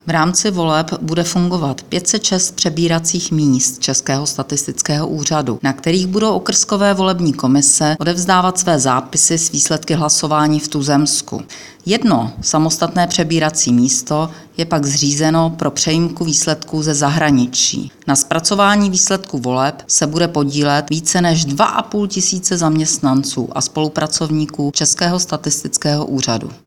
Vyjádření předsedkyně ČSÚ Ivy Ritschelové, soubor ve formátu MP3, 1.02 MB